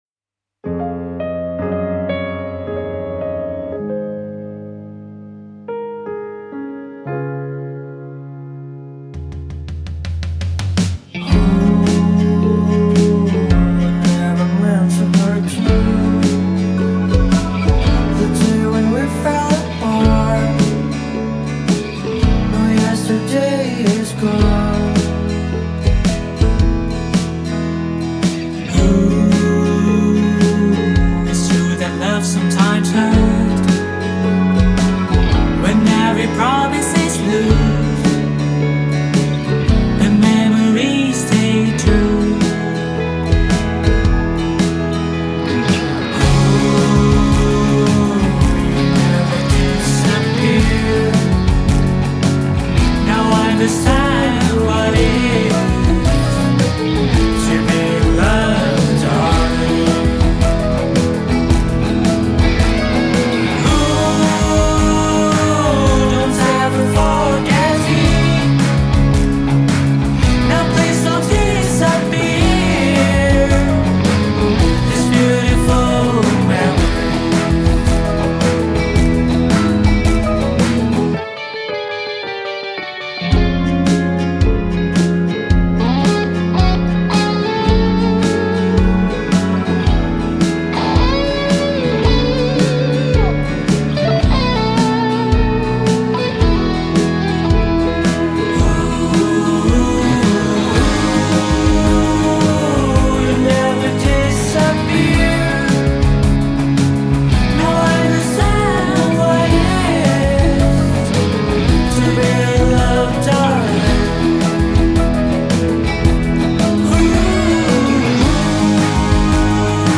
Bandung Alternative